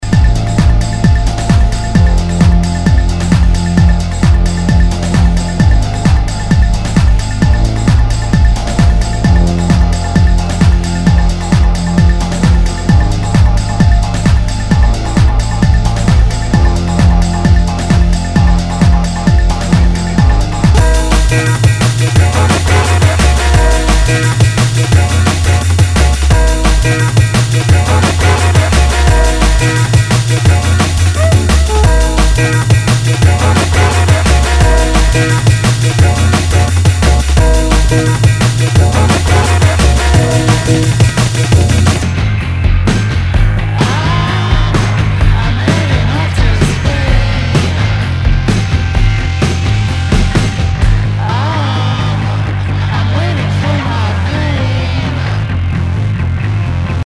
HOUSE/TECHNO/ELECTRO
ナイス！テック・ハウス / ドラムンベース！